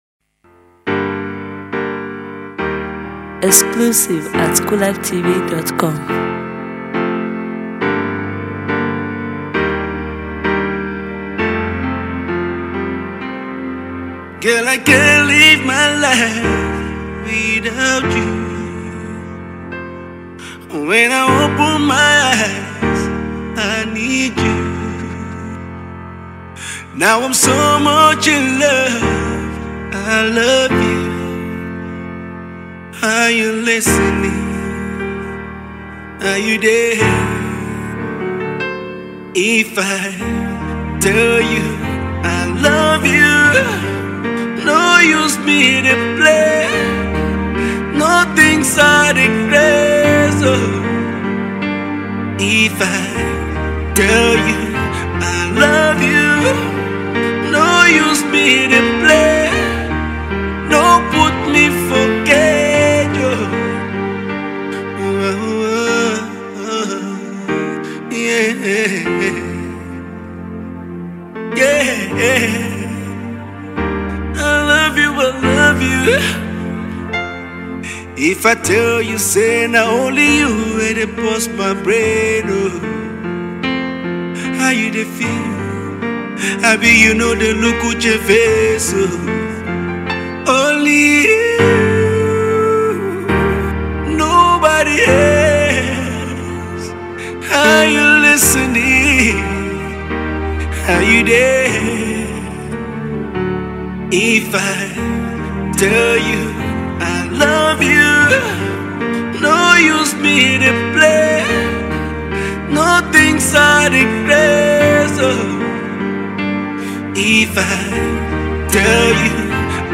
low-tempo love song